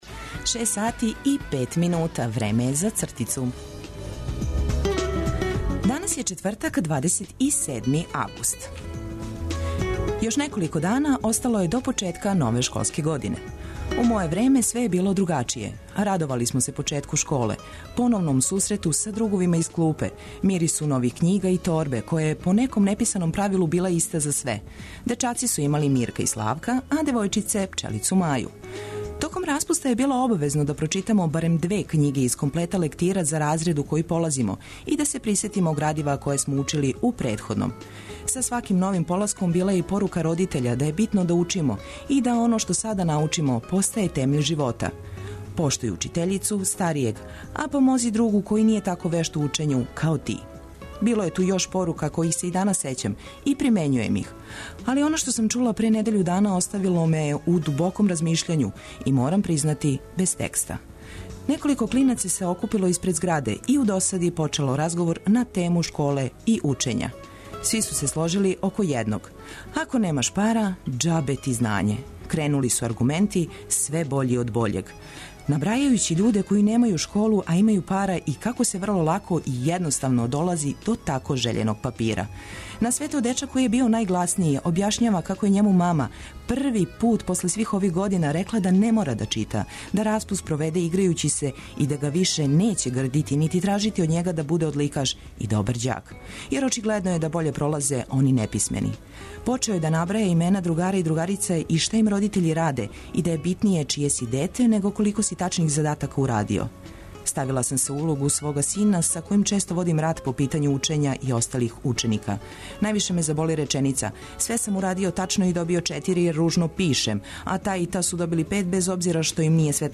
Док се лагано будите, ми смо ту да Вас информишемо о свим дешавањима. Цртица ће да Вам пожели добро јутро, графити ће Вам измамити осмех, а сервисне, културне и спортске информације, прошаране добром музиком улепшати дан.